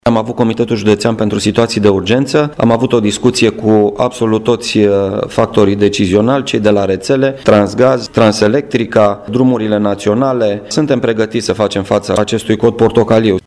Prefectul Marian Rasaliu a spus că autoritățile sunt pregătite pentru a face față acestei situații:
insert-prefect1.mp3